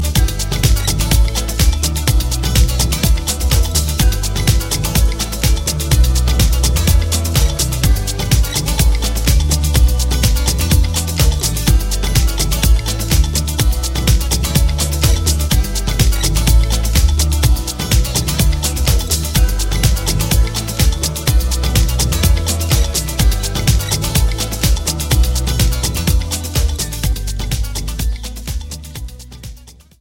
Inst